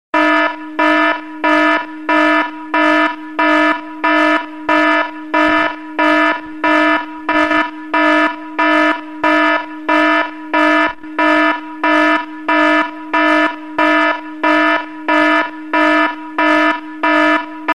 Categoría Alarmas